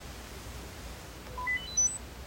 Новый звук звучит примерно так:
У некоторых тональность и длительность звука отличаются от примера.
Воспроизводится не из динамиков чехла AirPods, а именно из самих наушников, уже лежащих внутри кейса.
Звук странный, на «палитру» Apple совсем не похож.
Проще говоря, AirPods Pro 2 на последних актуальных прошивках научились проводить самодиагностику. Когда они лежат в кейсе, то громко воспроизводят специфический высокочастотный сигнал и одновременно слушают его своими же микрофонами.
Новый-звук-AirPods-Pro-2.mp3